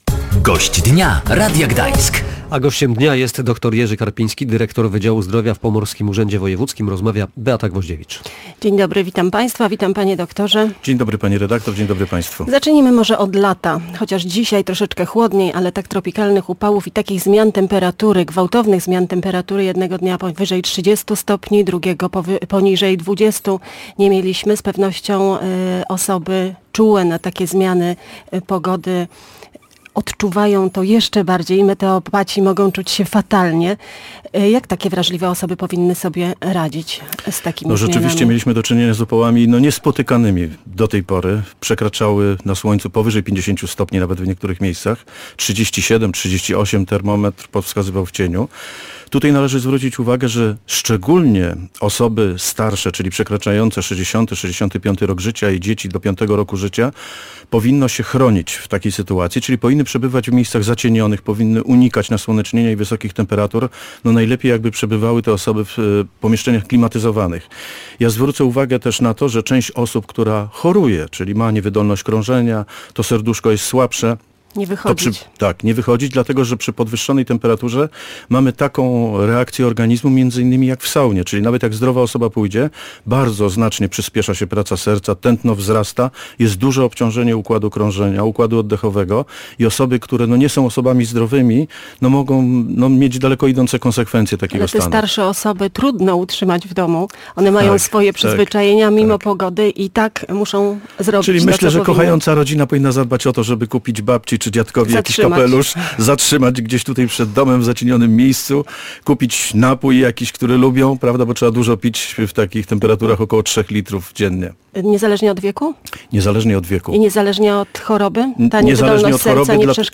O kondycję szpitali psychiatrycznych zapytaliśmy Gościa Dnia Radia Gdańsk dr. Jerzego Karpińskiego, dyrektora Wydziału Zdrowia w Pomorskim Urzędzie Wojewódzkim.